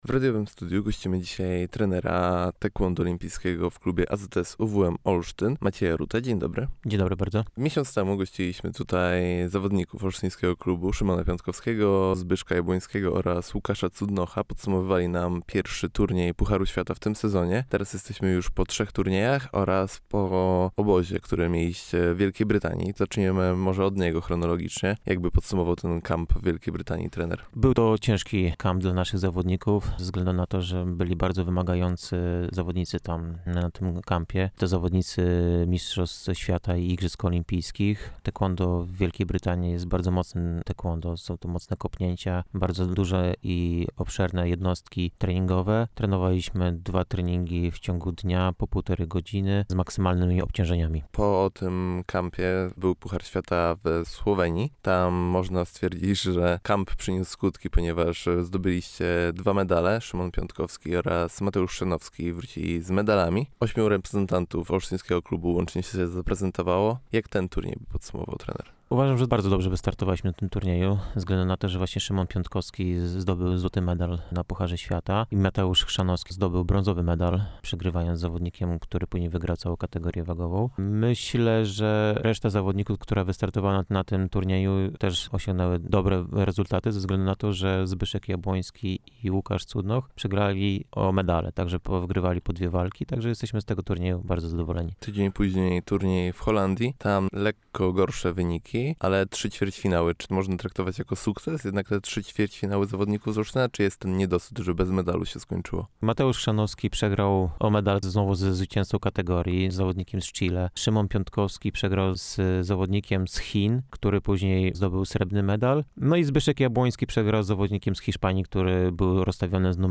W naszym studiu